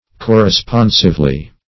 -- Cor`re*spon"sive*ly , adv.
corresponsively.mp3